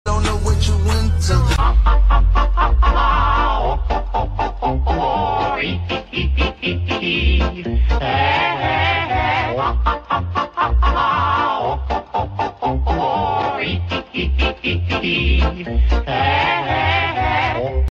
heh heh heh sound effects free download